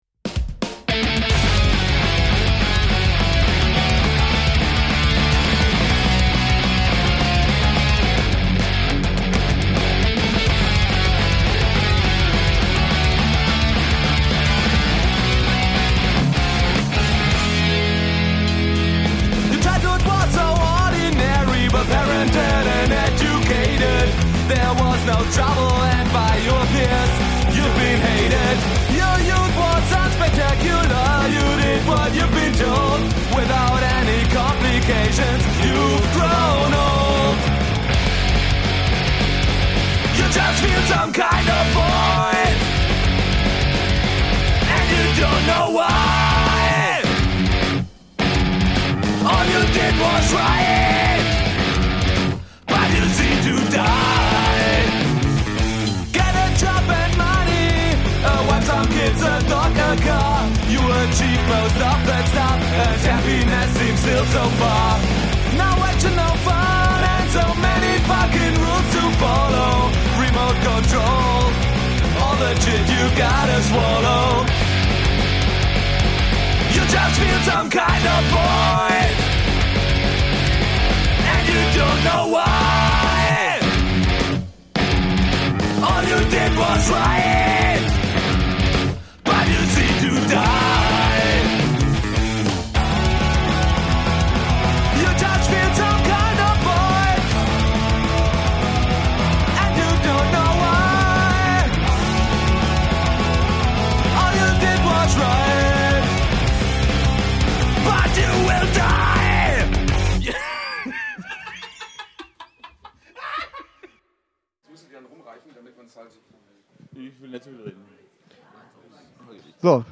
Letzte Episode Interview mit Planet Watson beim Punk Rock Holiday 1.6 10. August 2016 Nächste Episode download Beschreibung Teilen Abonnieren Planet Watson haben uns zum Interview beim Punk Rock Holiday 1.6 besucht. Interviewer sowie Band hatten Bock darauf, Müll zu quatschen.
interview-mit-planet-watson-beim-punk-rock-holiday-1-6-mmp.mp3